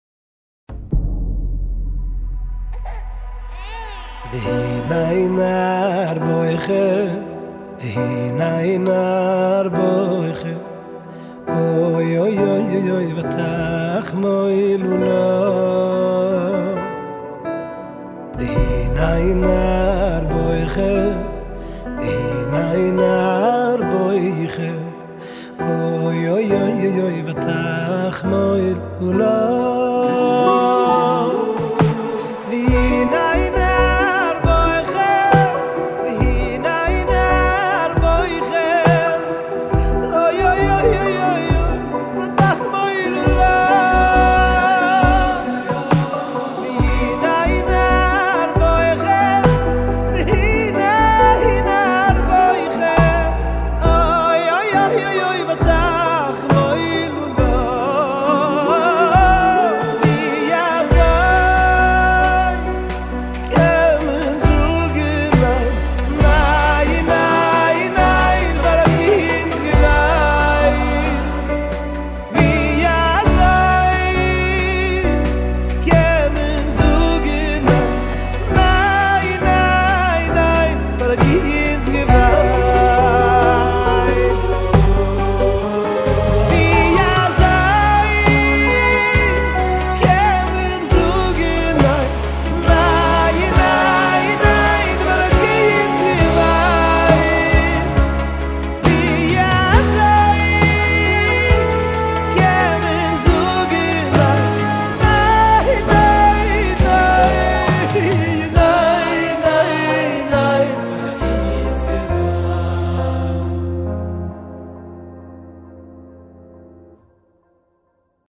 הקלידן
הזמר